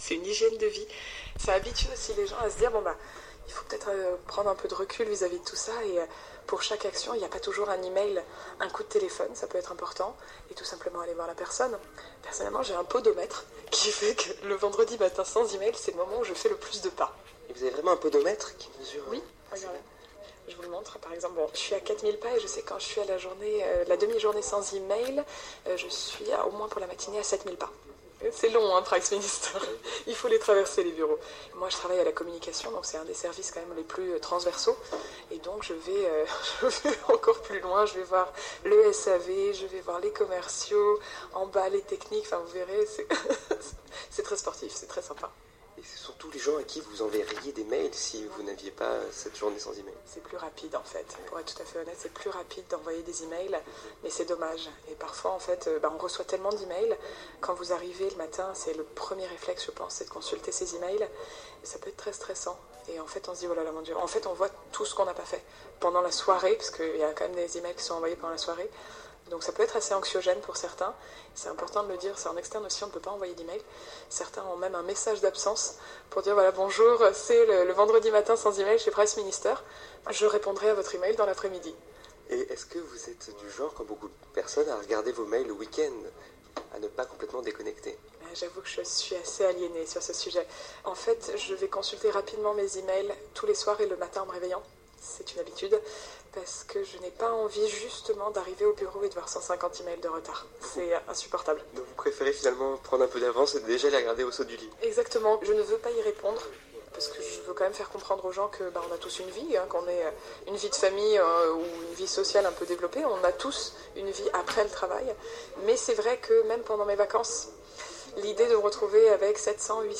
Cela m’a remis en mémoire une émission entendue il y a deux ans, sur ces entreprises qui instituent des demi-journées ou même parfois des journées sans mails pour leurs salariés. C’est ce dont parlait cette jeune femme proche de la trentaine dont j’avais gardé le témoignage sympathique.